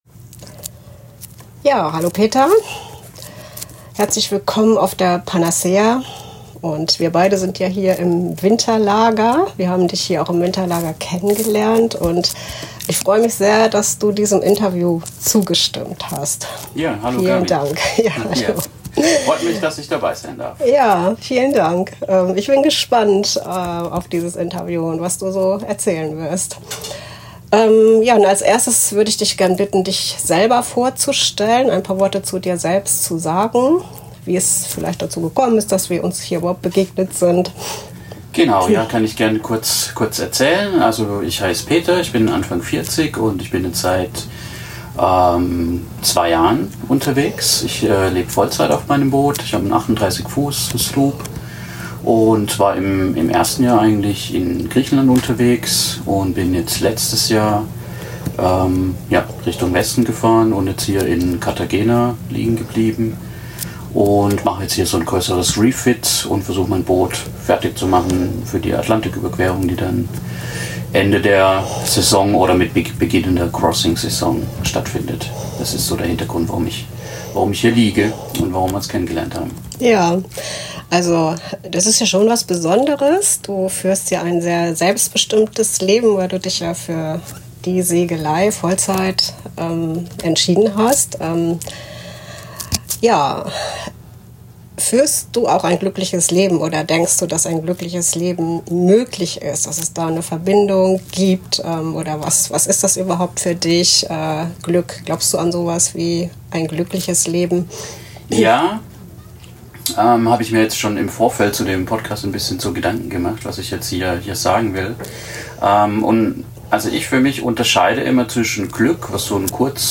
Ein inspirierendes Gespräch über die Kunst, mit sich selbst im Reinen zu sein und den Mut, eigene Träume aktiv zu verwirklichen.